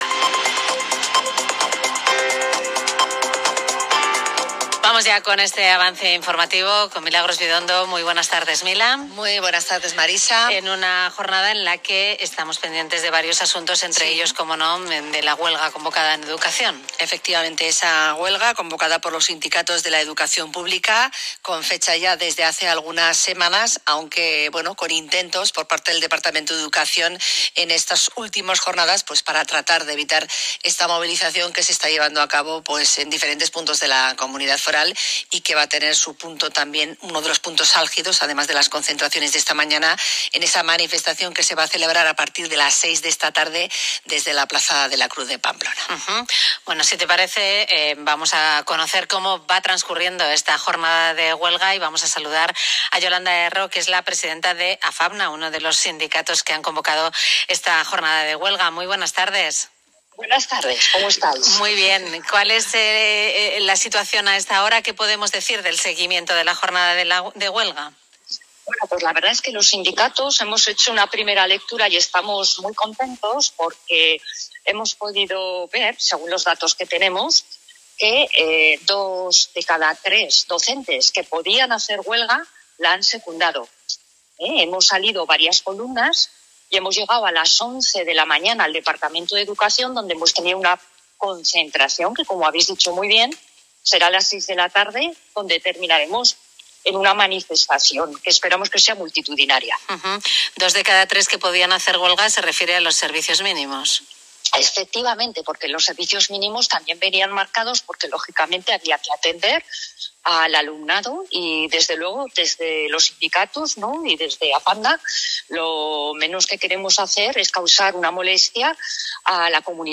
Desde el sindicato os acercamos el corte de Onda Cero que tuvo lugar esta mañana del 26 de septiembre, sobre la huelga de educación: